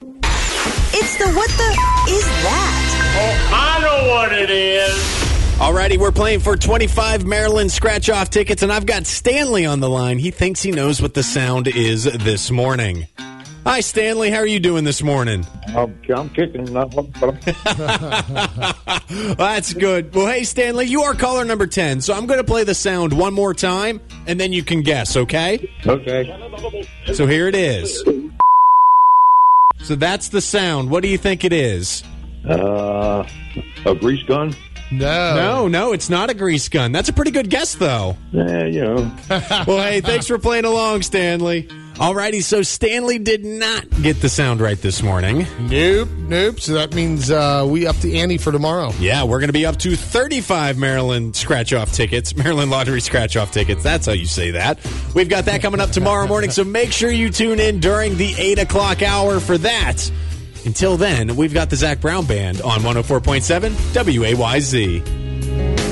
Tune in tomorrow morning during the 8 o’clock hour to hear it again (it is bleeped out here)